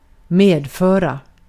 Ääntäminen
Vaihtoehtoiset kirjoitusmuodot (vanhentunut) bringe (vanhentunut) brynge Synonyymit get contribute Ääntäminen brittisk engelska: IPA : /ˈbɹɪŋ/ US : IPA : [ˈbɹɪŋ] UK US : IPA : /ˈbɹiːŋ/ also: IPA : /ˈbɹiːŋ/